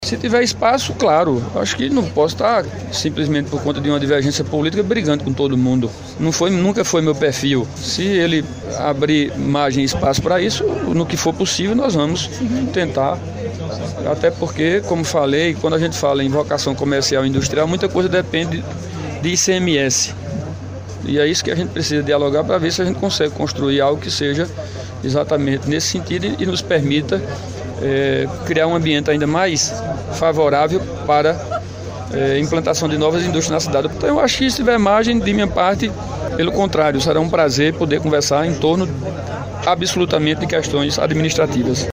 As declarações foram na manhã desta segunda-feira 07, quando o prefeito anunciava um pacote de obras para este mês de janeiro, dentro do Programa Cresce Campina 3 no valor de R$ 30 milhões.